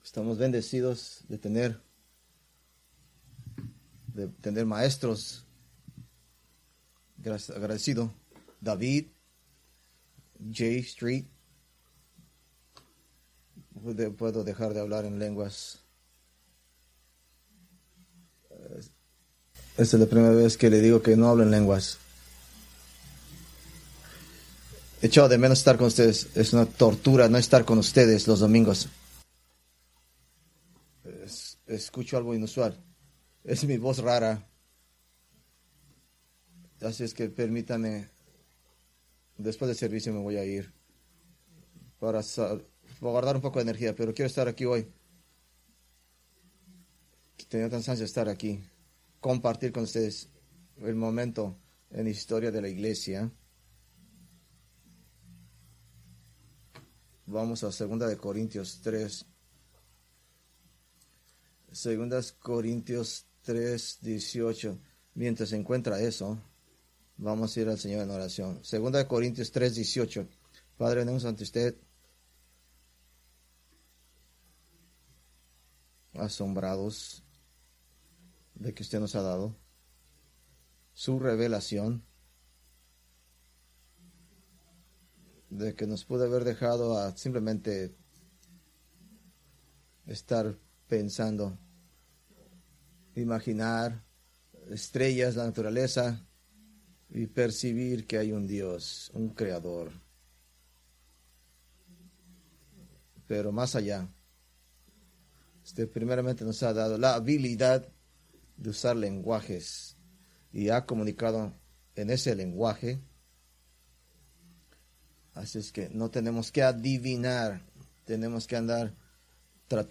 Preached July 27, 2025 from 2 Corintios 3:18-4:1-6
fecha: 27 Jul, 2025 groupo: Sermones varios 2025 mas: Download MP3 | iTunes